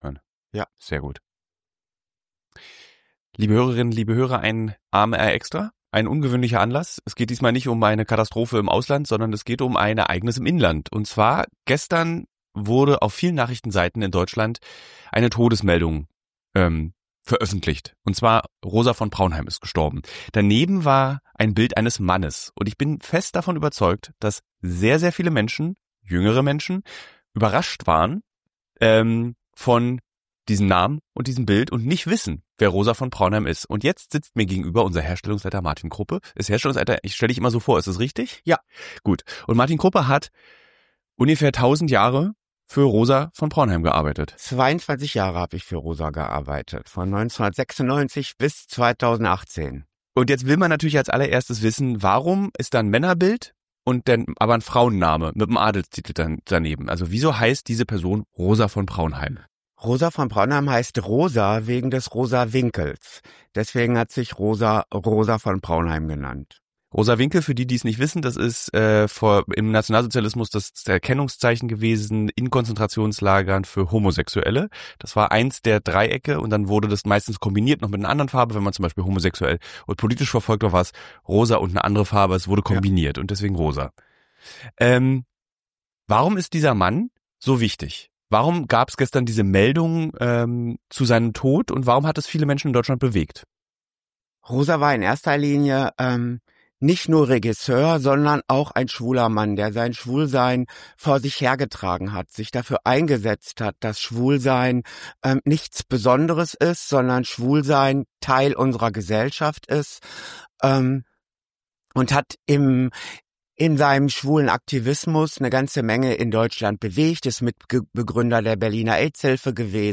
Ein persönlicher Nachruf auf Rosa von Praunheim
Das Gespräch erzählt aber nicht nur von politischem Impact, sondern auch von persönlichen Wegen.